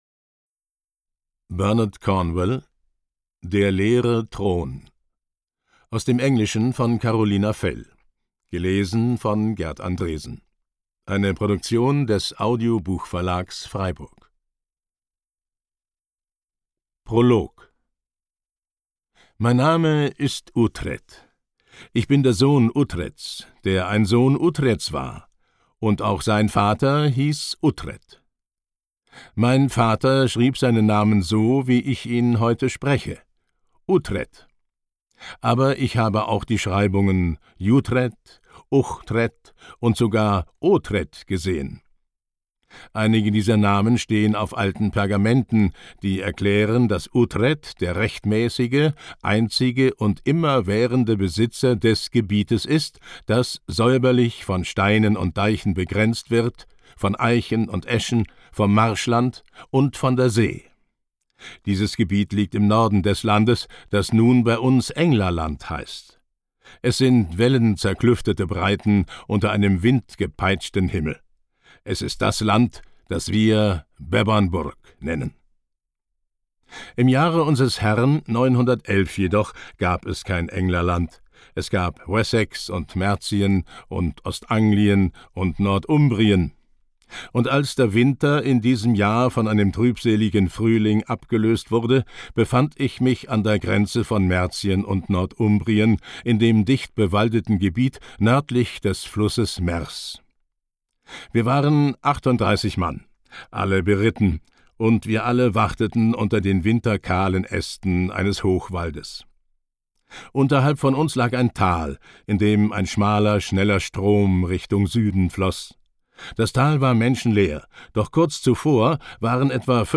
Er überzeugt als stimmgewaltiger Ich-Erzähler der "Uhtred-Saga" von Bernard Cornwell.